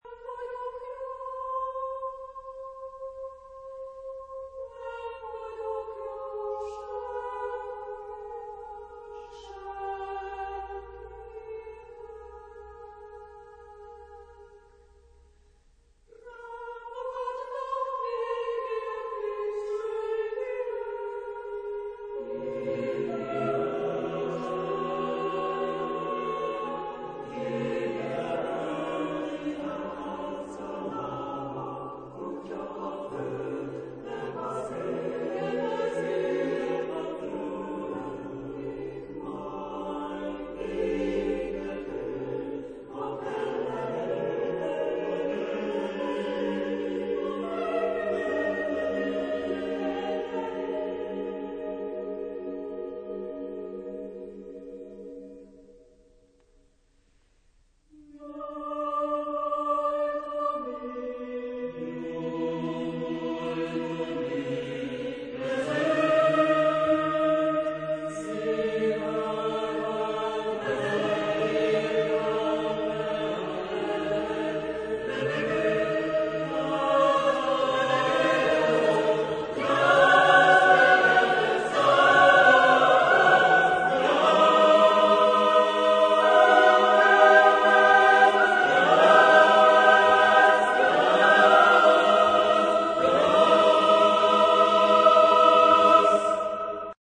Epoque: 20th century  (1970-1979)
Genre-Style-Form: Contemporary ; Choir
Type of Choir: SSAATB  (6 mixed voices )
Tonality: atonal